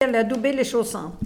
Localisation Saint-Maixent-de-Beugné
Catégorie Locution